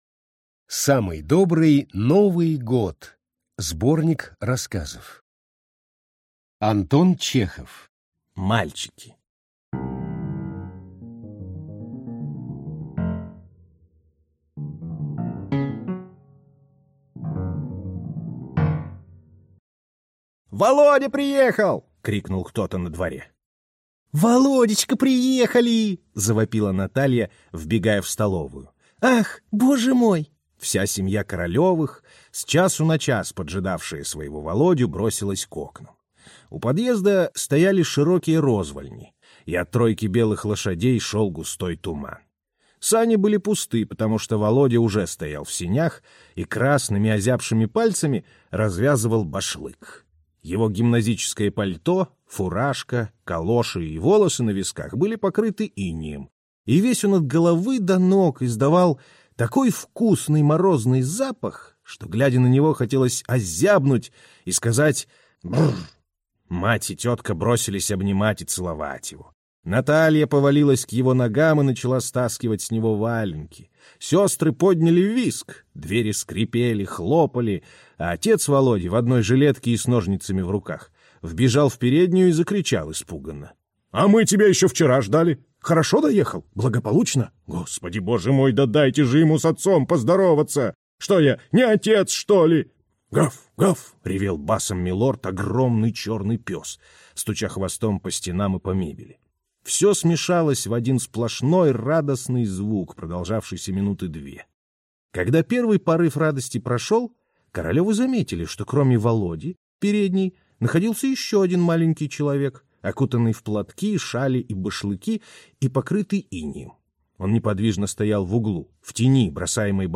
Аудиокнига Самый добрый Новый год | Библиотека аудиокниг